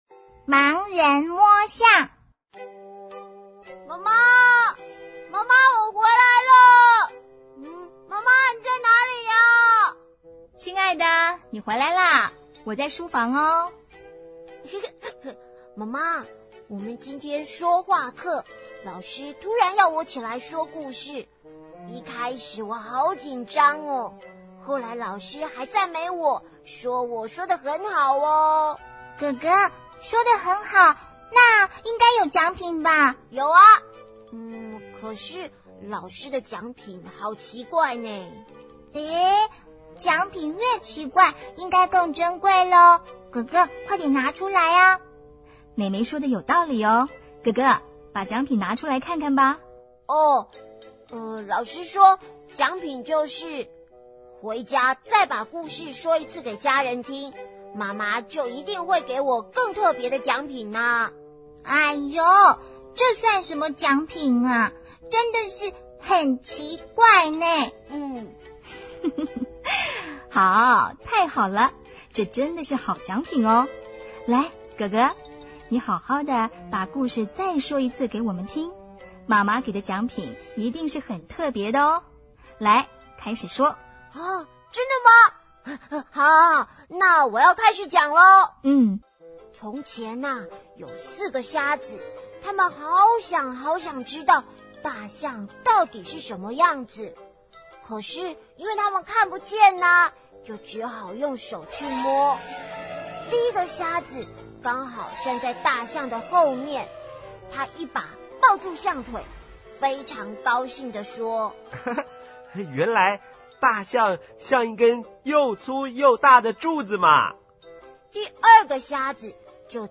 CD 每則成語均錄製成好聽生動的「廣播劇」，增加學習效果。